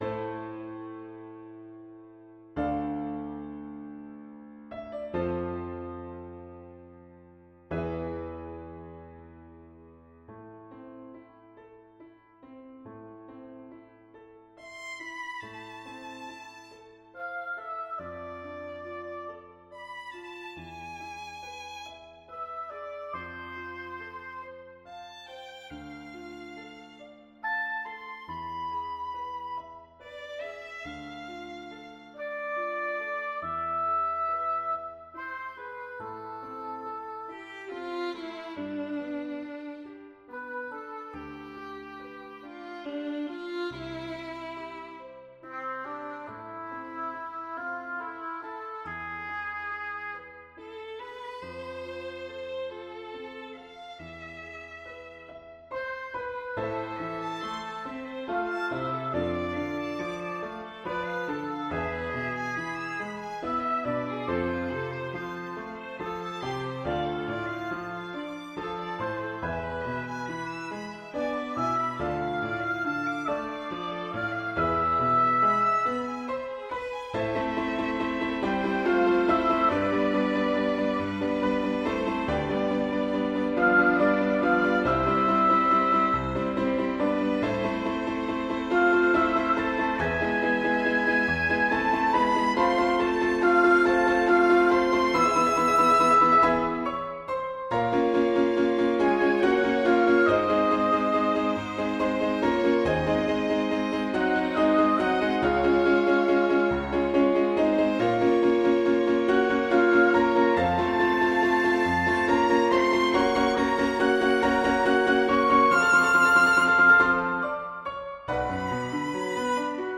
Five Original Compositions for chamber music
5 COMPOSIZIONI DI MUSICA DA CAMERA
PIANOFORTE, VIOLINI, CELLO, OBOE.